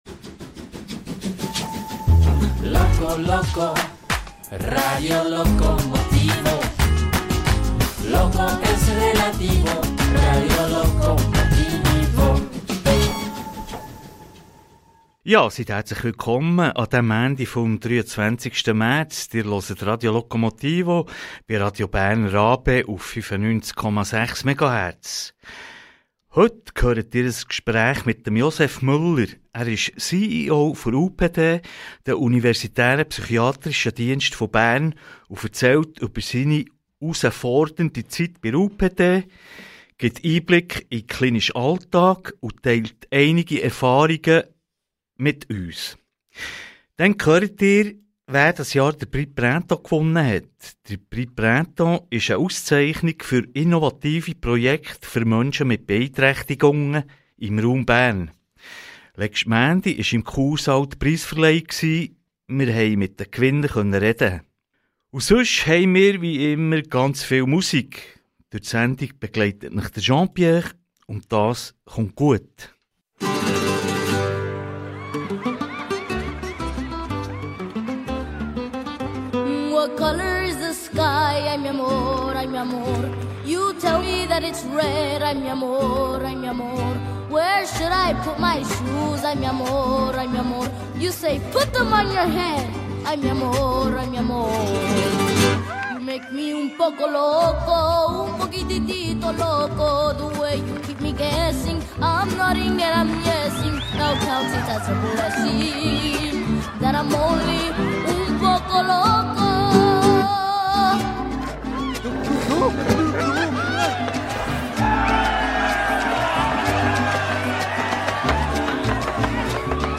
Wir waren vor Ort, letzten Montag im Kursaal, an der Preisverleihung des Prix Printemps 2026.